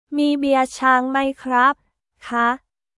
ミー ビア チャーン マイ クラップ／カー